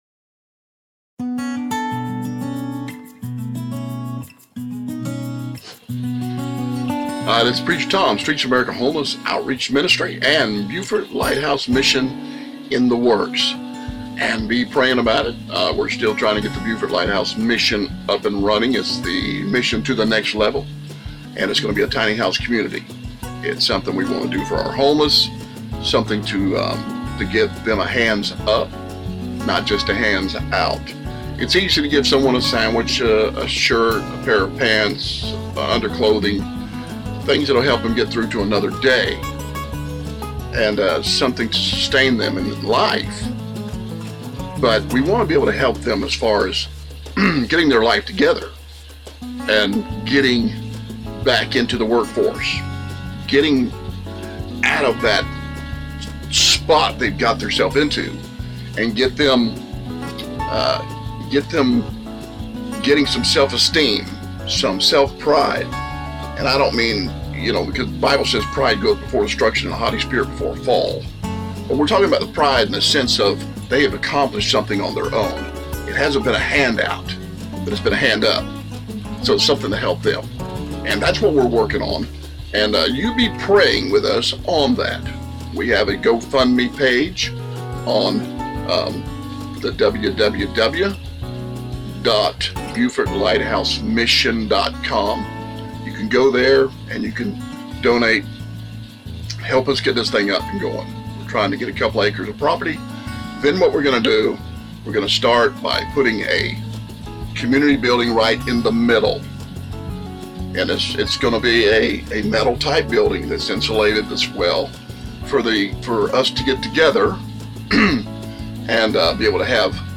This is a Simple Devotional Bible study to help Christians improve in their walk with the Lord.